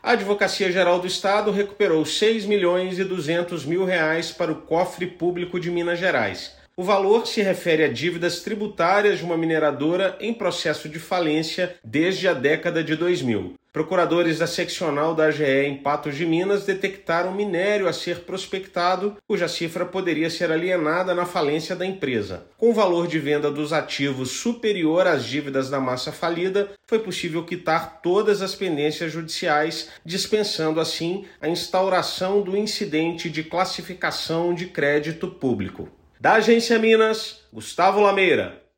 A Advocacia-Geral do Estado (AGE-MG) recuperou R$ 6,2 milhões para o erário de Minas Gerais junto a uma mineradora em processo de falência desde a década de 2000. O valor se refere a dívidas tributárias e encerra mais de 15 processos que se arrastavam há décadas no Judiciário. Ouça matéria de rádio.